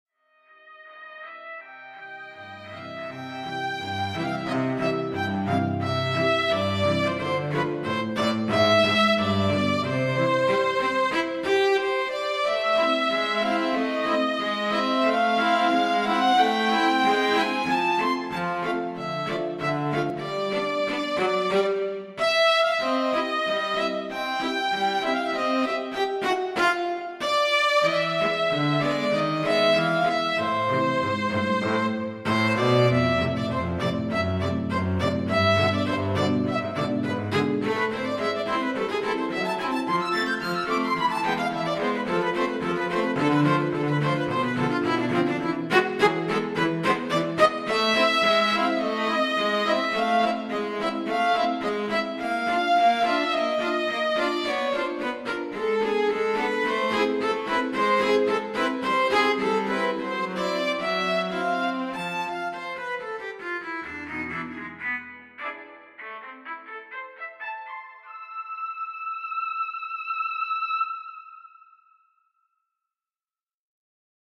A short play, ending the third act of my [yet unfinished] opera, the curtain and - most importantly - an intermission!